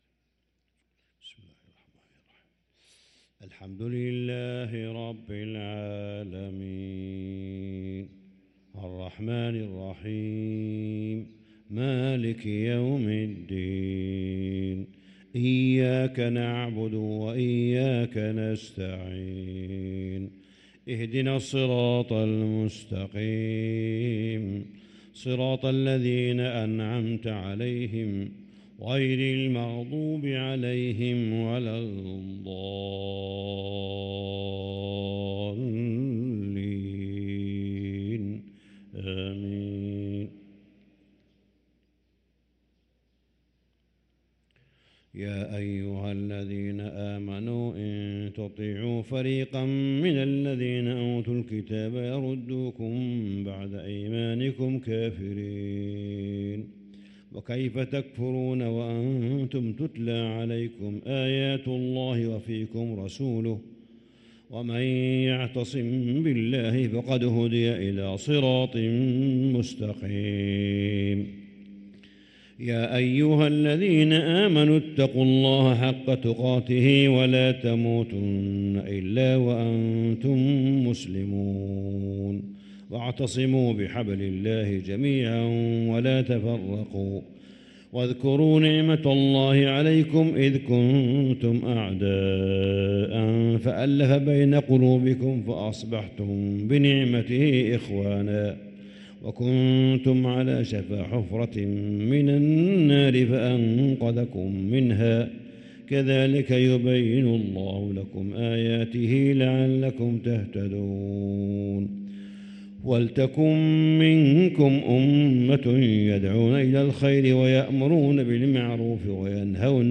صلاة الفجر للقارئ صالح بن حميد 10 جمادي الآخر 1445 هـ
تِلَاوَات الْحَرَمَيْن .